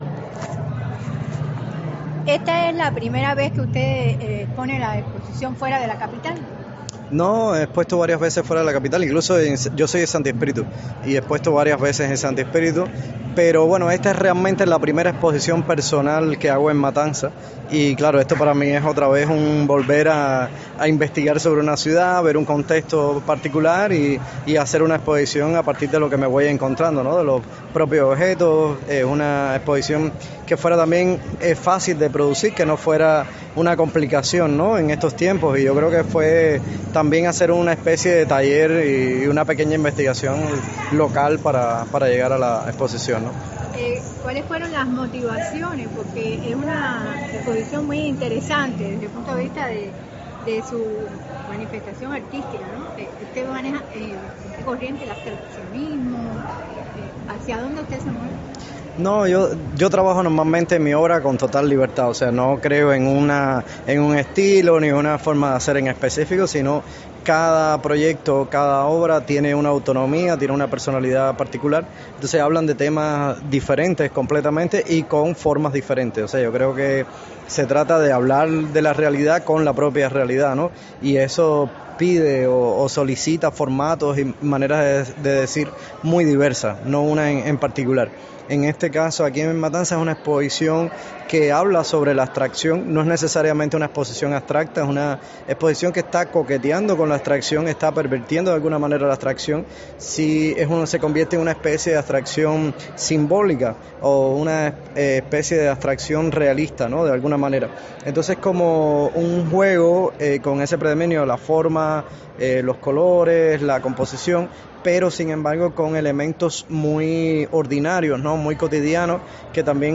Durante la inauguración, en entrevista para Radio 26, profundizamos sobre su exposición y presencia en el universo artístico cubano.